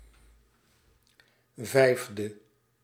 Ääntäminen
IPA: /ˈvɛi̯vdə/